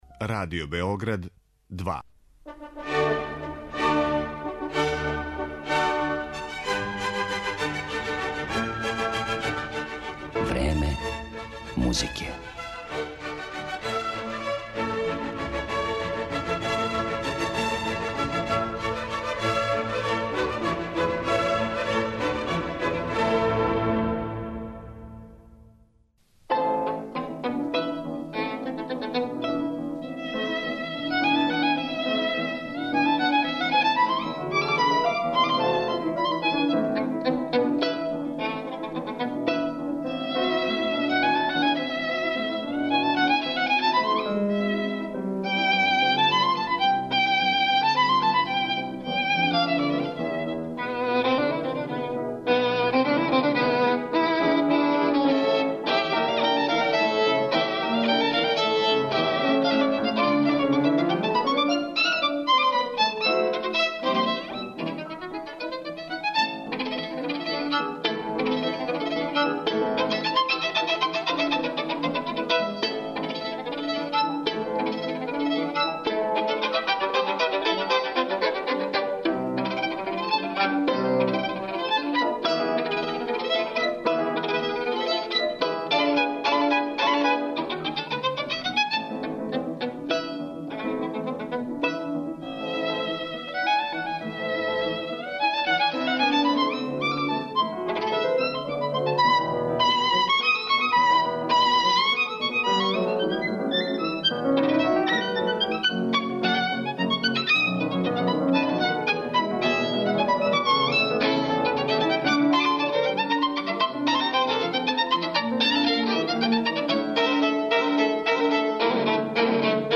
у коме основу чине елементи богатог шпанског музичког фолклора